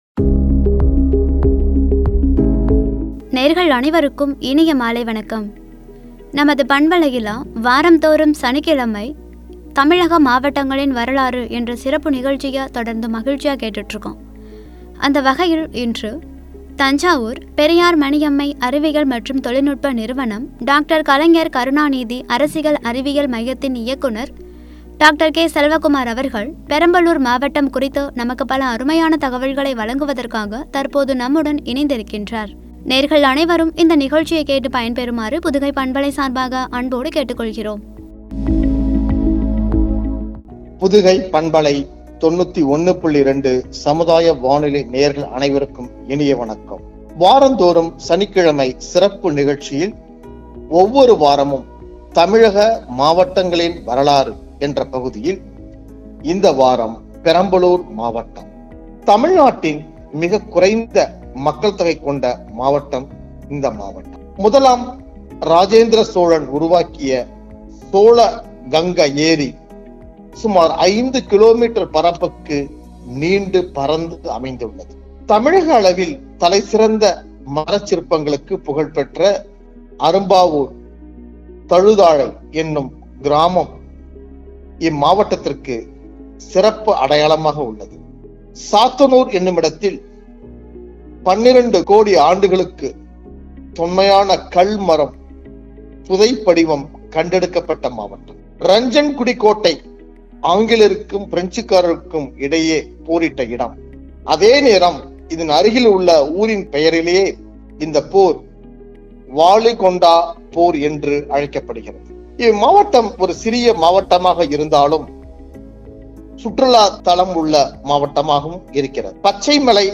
“பெரம்பலூர்”என்ற தலைப்பில் வழங்கிய உரை.